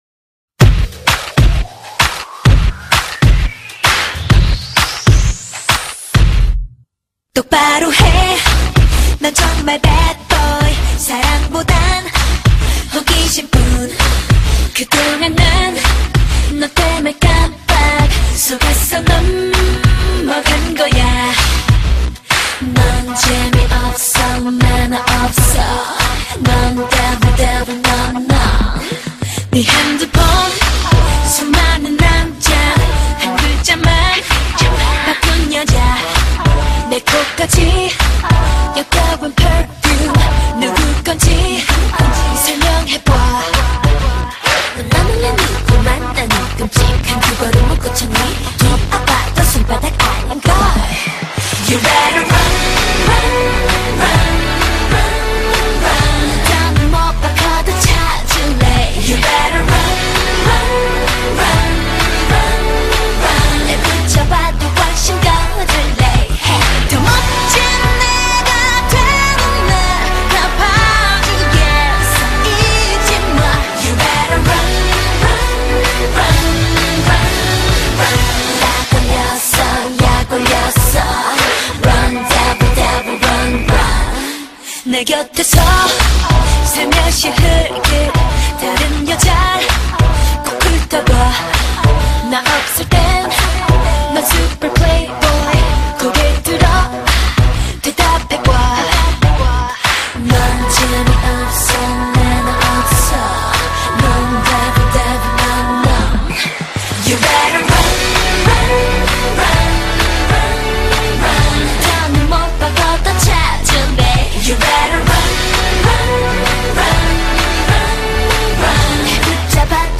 They are all very thin, pretty, and can sing well.